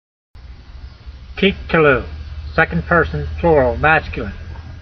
My reading (voice) in modern Israeli style is only good enough to get you started.
teek-te-lu